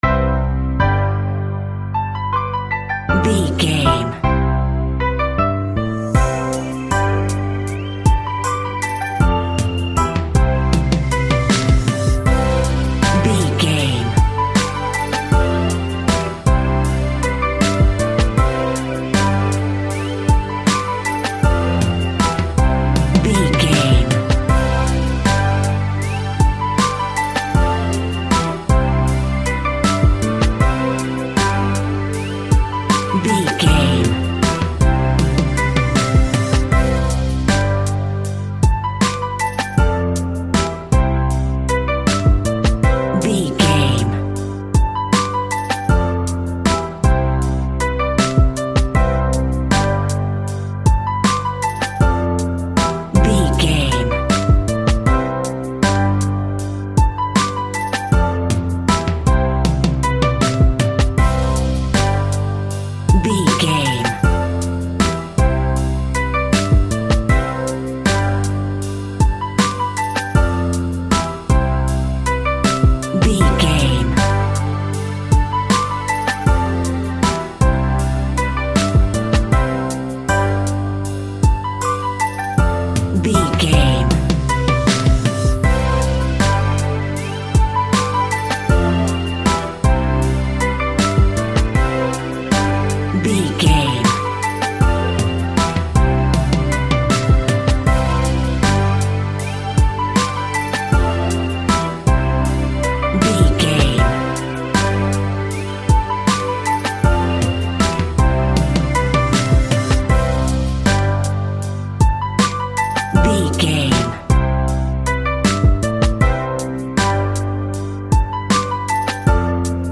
Hip Hop Gospel Fusion.
Ionian/Major
chilled
laid back
groove
hip hop drums
hip hop synths
piano
hip hop pads